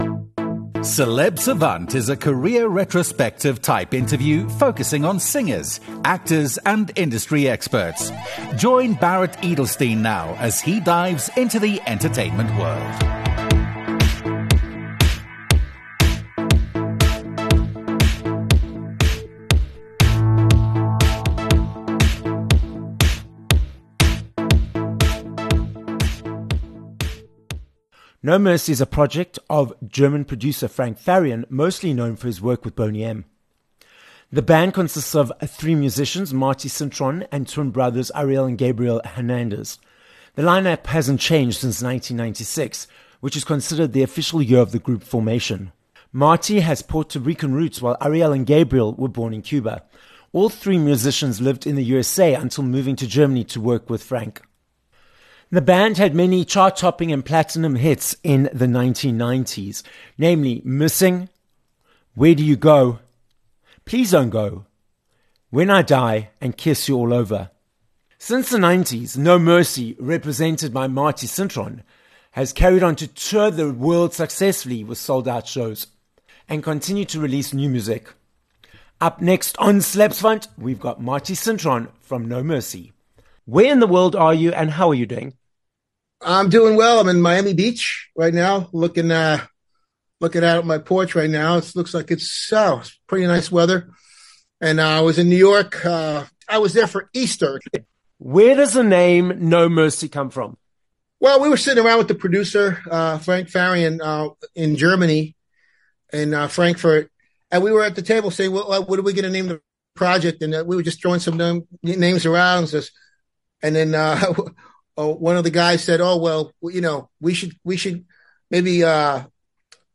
18 Jun Interview with Marty Cintron (No Mercy)
'Where Do You Go, My Lovely' - stay exactly where you are, because on this episode of Celeb Savant, we will be chatting with American singer and songwriter Marty Cintron from No Mercy. Marty tells us where the name No Mercy comes from, and how Frank Farian created the band with Marty, resulting in 30 years of success in the music industry.